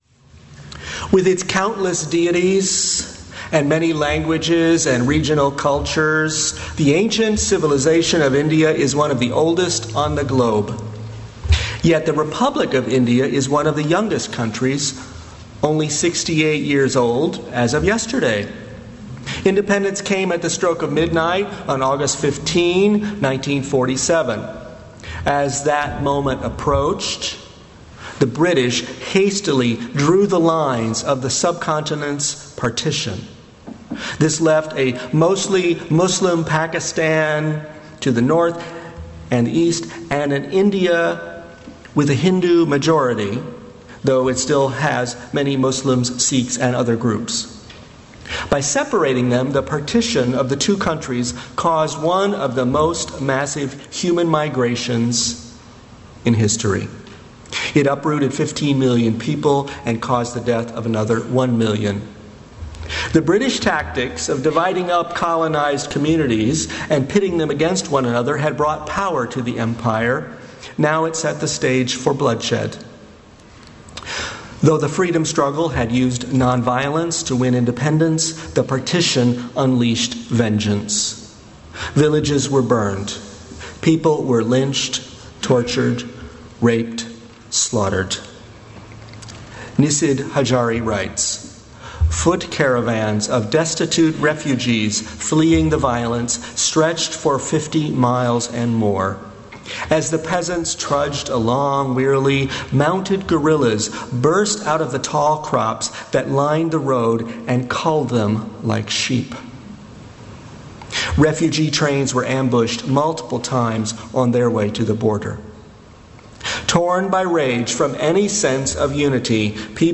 Unitarian Universalist Society of Sacramento